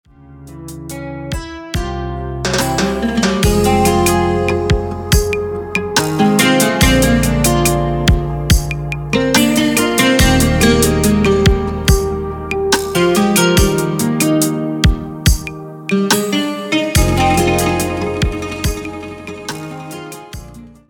• Type : Instrumental
• Bpm : Andante
• Genre : Oriental / R&B